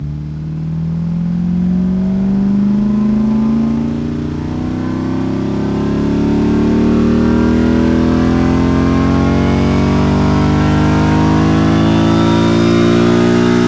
rr3-assets/files/.depot/audio/Vehicles/v8_14/v8_14_accel.wav
v8_14_accel.wav